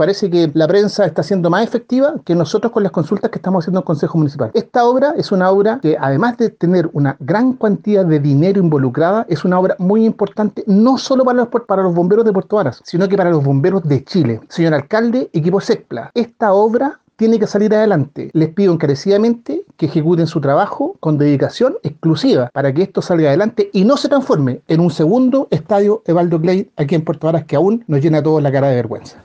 El edil Marcelo Salazar dijo que en reiteradas ocasiones solicitó en el Concejo Municipal el estado de avance de las grandes obras que se efectúan en la comuna.